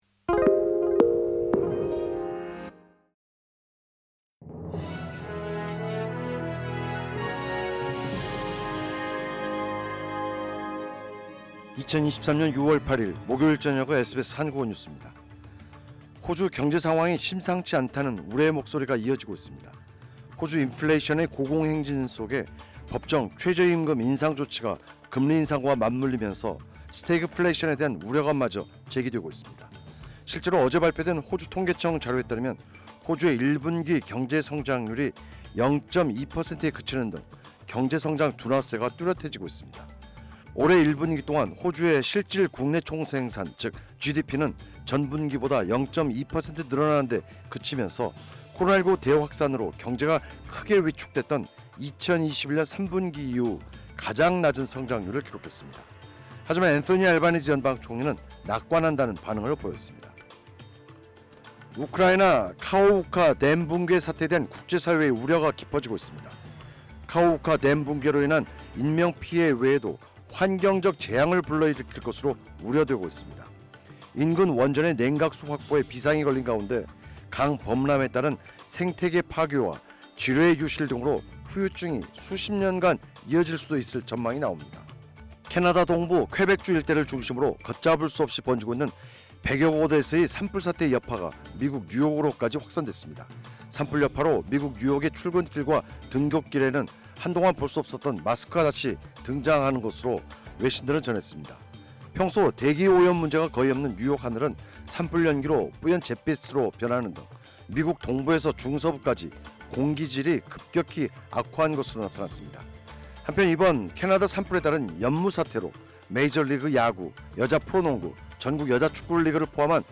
2023년 6월 8일 목요일 저녁 SBS 한국어 뉴스입니다.